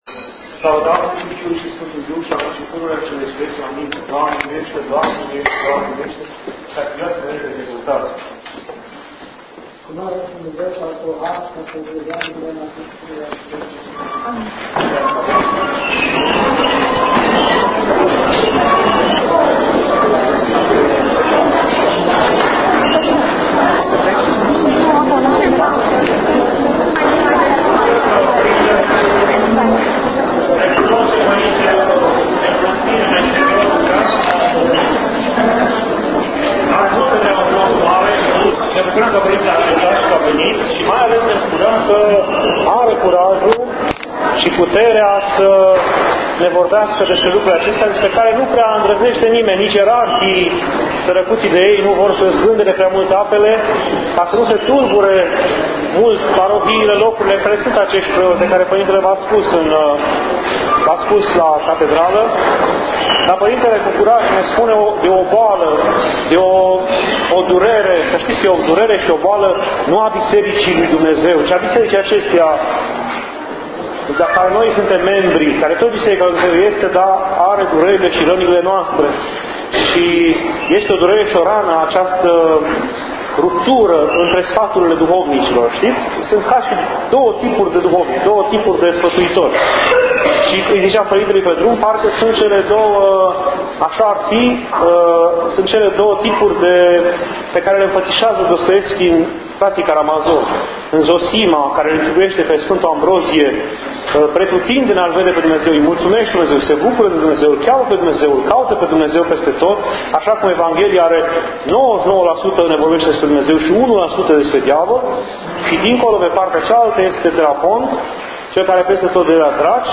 Liturghie